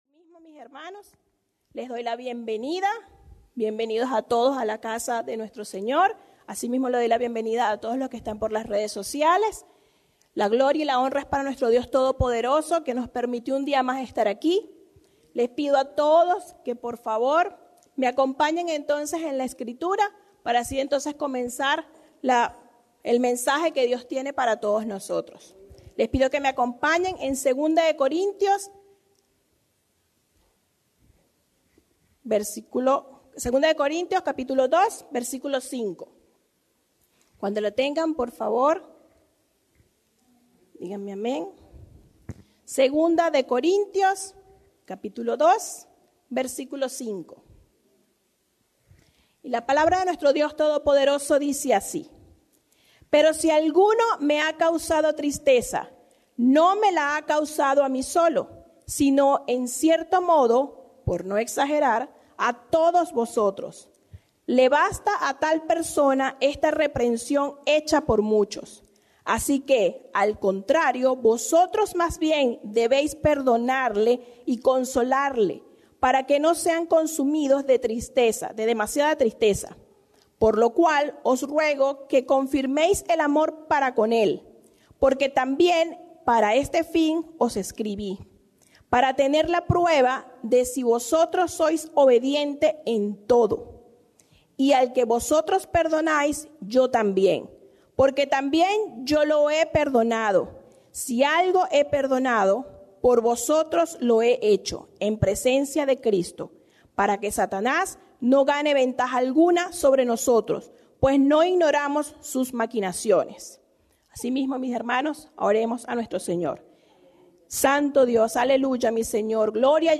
Predica
Norristown,PA